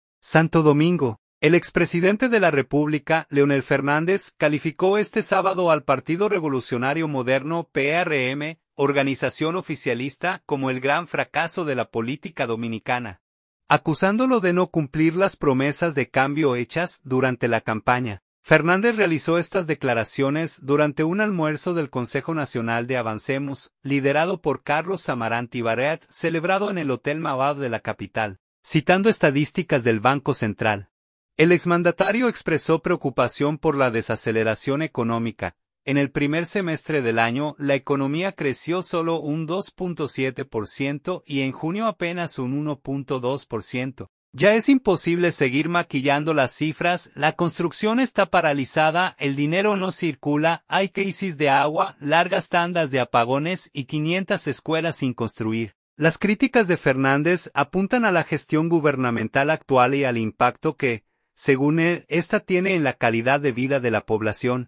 Fernández realizó estas declaraciones durante un almuerzo del Consejo Nacional de AVANCEMOS, liderado por Carlos Amarante Baret, celebrado en el Hotel Mauad de la capital.